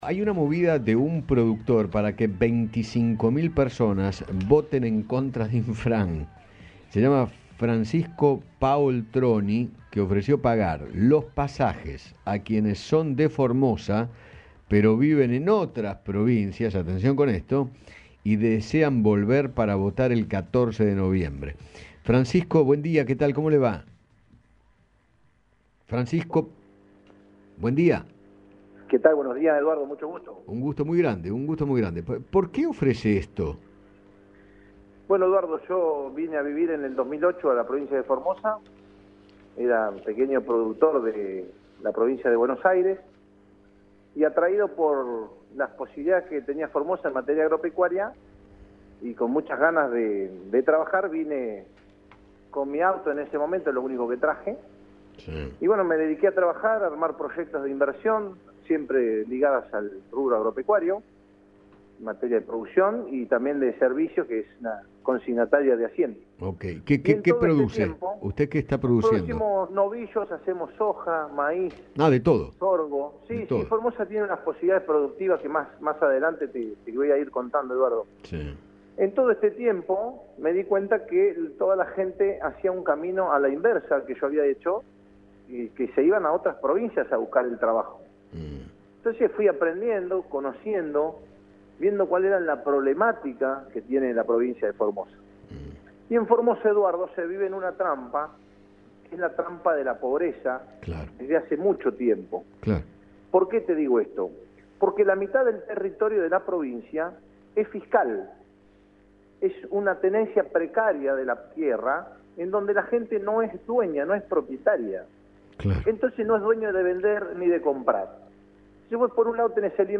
conversó con Eduardo Feinmann sobre el complicado presente de la provincia